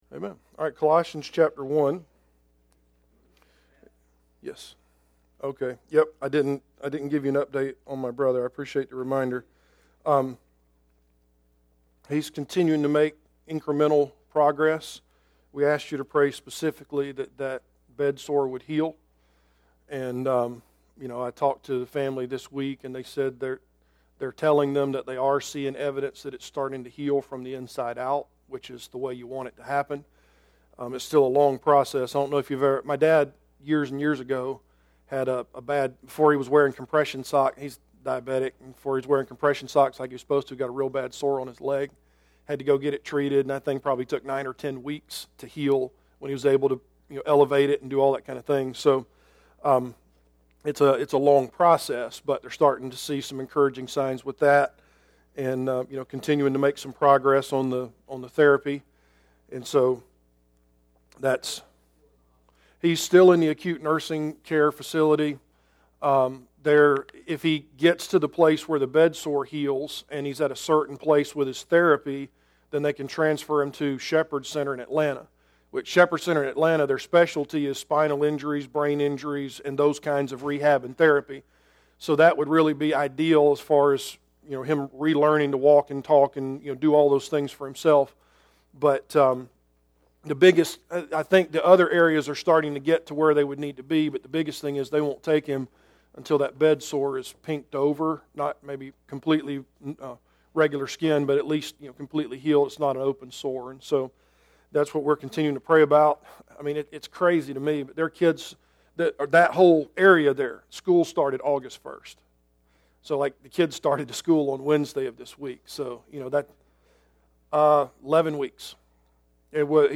Service Type: Adult Sunday School Class